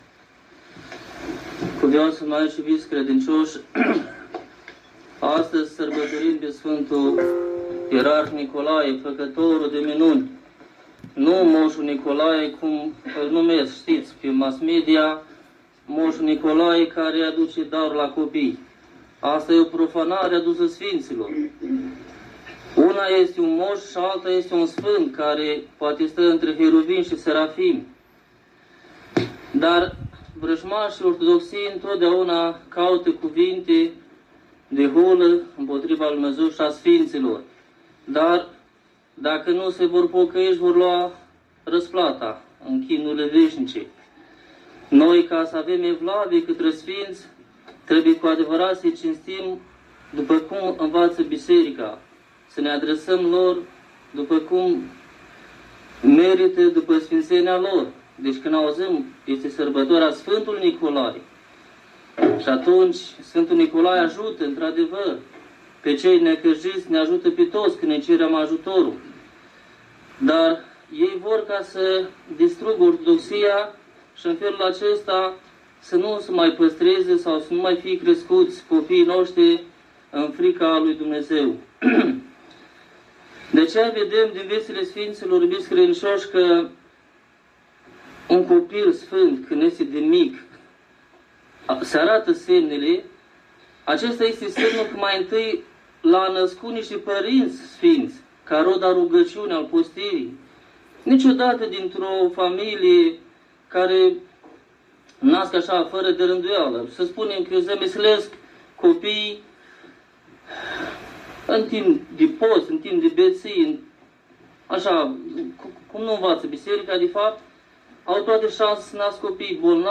Predica
la praznicul Sfântului Ierarh Nicolae – 6 decembrie 2021